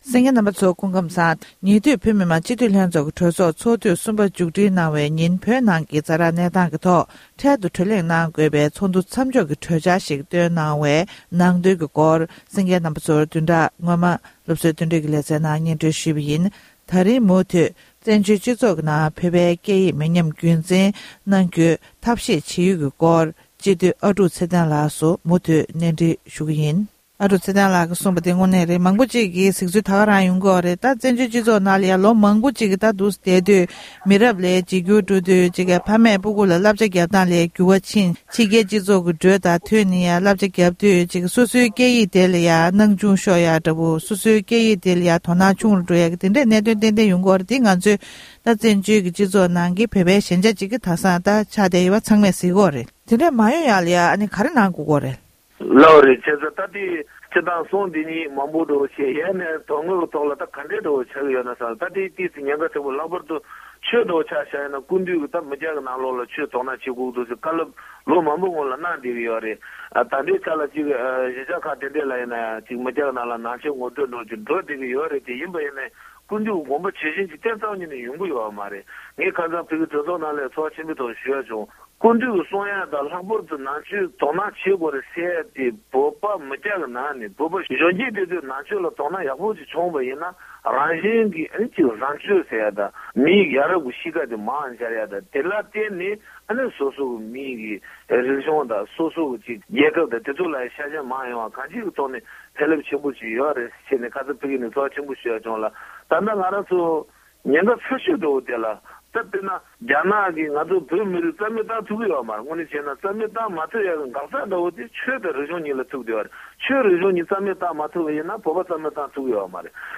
གནས་འདྲི་ཞུས་པའི་ལེ་ཚན